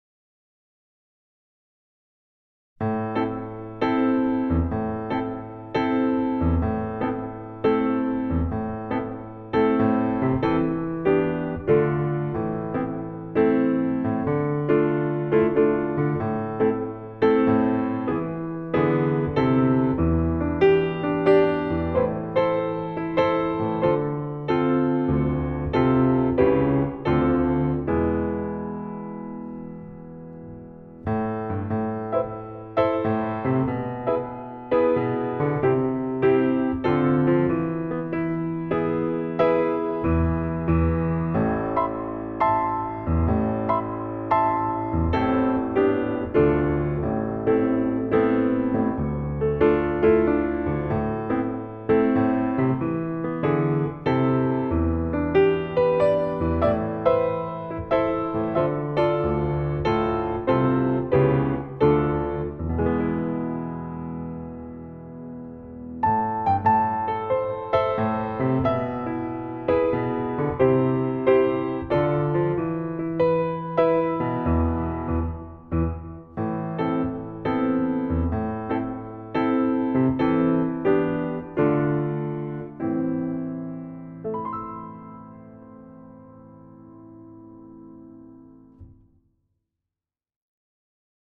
Vox Populi Choir is a community choir based in Carlton and open to all comers.
Give_me_wings_Backing.mp3